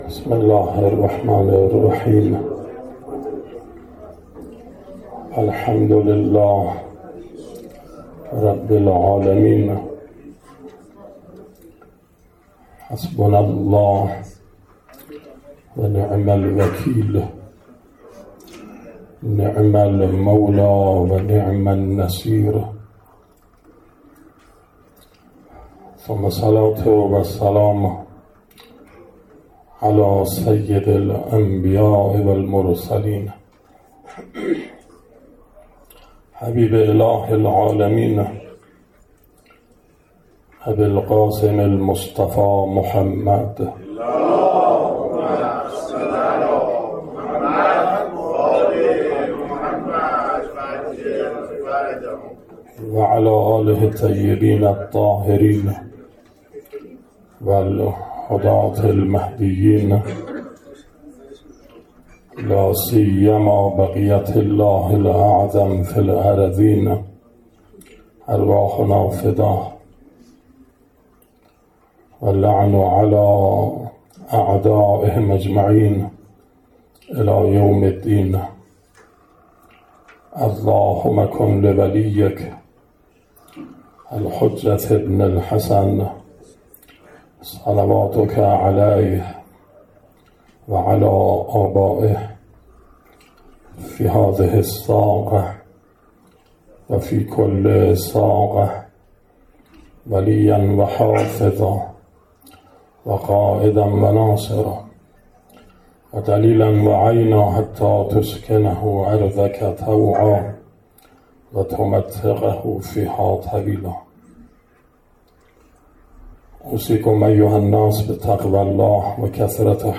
به گزارش خبرگزاری حوزه از کرمان، شصتمین جلسه از سلسله مباحث شرح نهج‌البلاغه روز دوشنبه ۲۸ مهرماه ۱۴۰۴ با حضور جمعی از طلاب، روحانیون و علاقه‌مندان به معارف علوی در حسینیه بقیةالله‌الاعظم(عج) واقع در خیابان ۲۴ آذر کرمان برگزار شد.